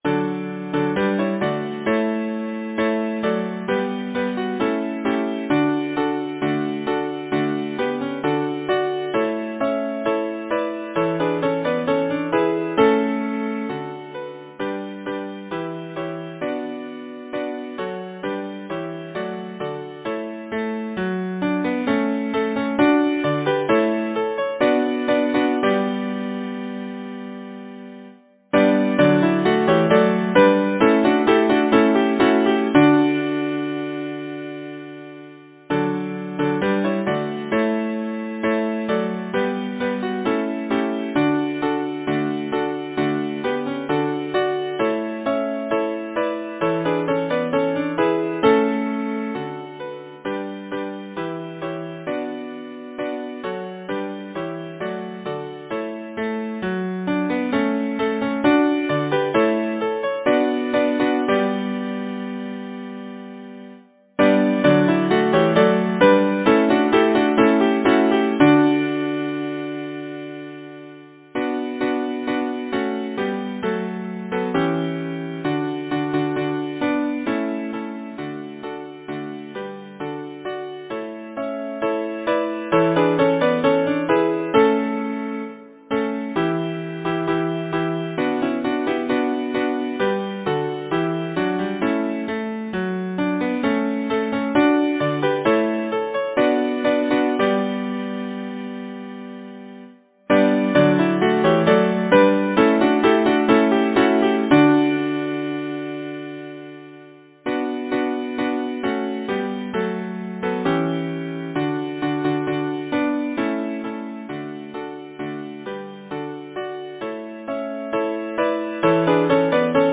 Number of voices: 5, 4vv Voicings: ATTTB or SATB Genre: Secular, Glee
Language: English Instruments: A cappella